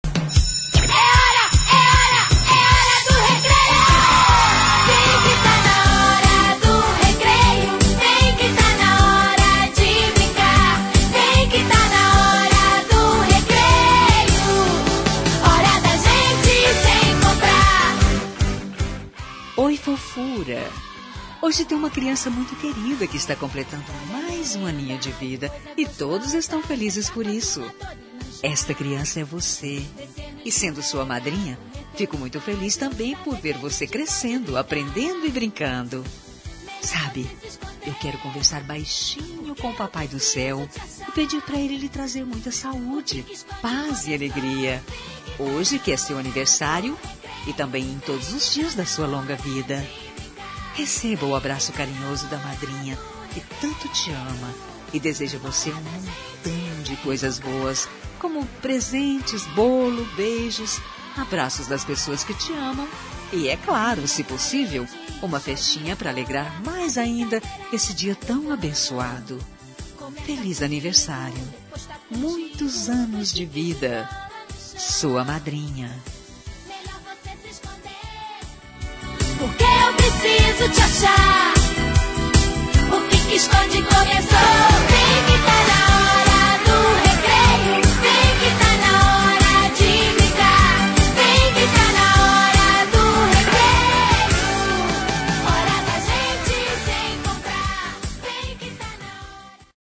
Aniversário de Afilhado – Voz Feminina – Cód: 2359 – Criança
2359-afilhado-fem.m4a